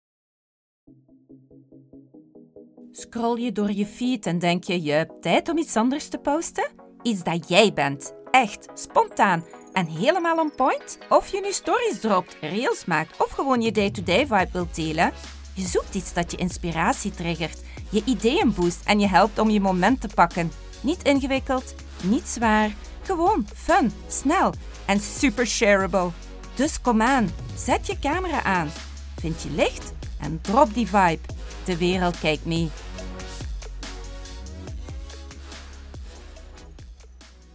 Vous pourrez compter sur un enregistrement impeccable de la voix néerlandaise flamande (belge), la voix française, la voix anglaise ou la voix espagnole grâce à l’équipement professionnel dont je dispose.
Démo Pub – Exemple voix off en Néerlandais (Flamand )